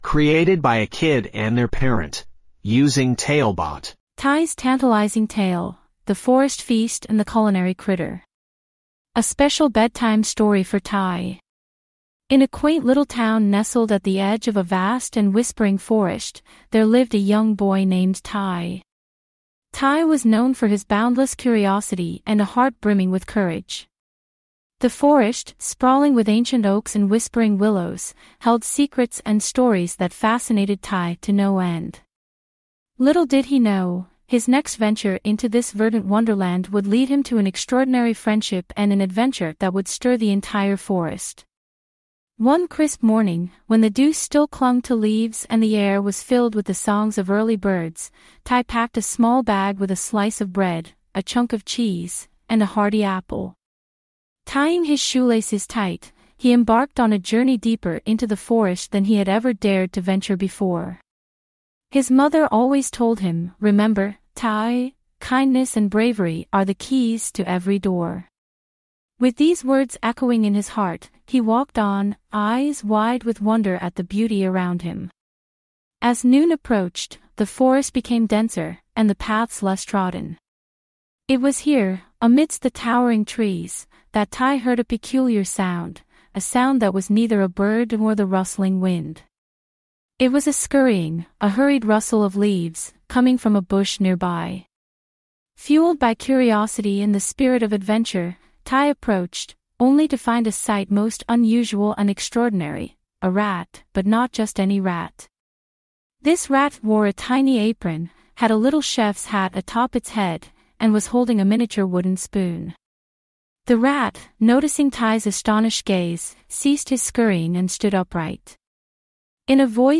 5 minute bedtime stories.
TaleBot AI Storyteller
Write some basic info about the story, and get it written and narrated in under 5 minutes!